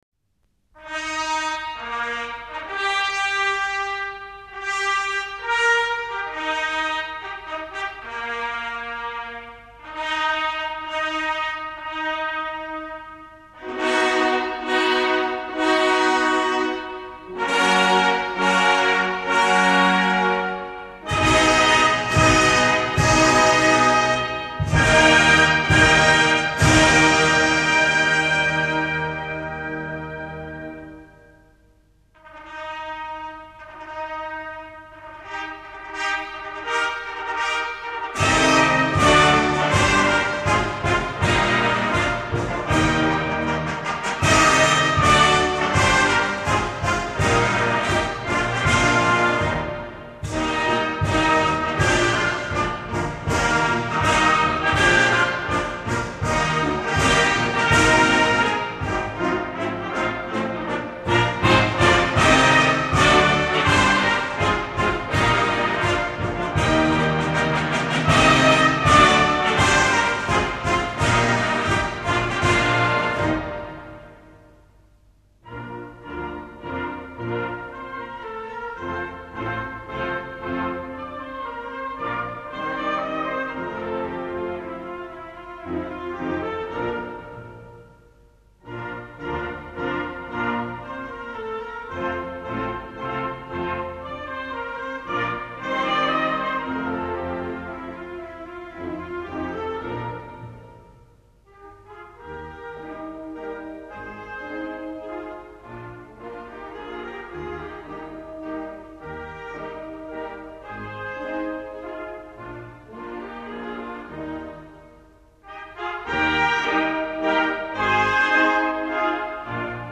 Gattung: Melodienfolge
Besetzung: Blasorchester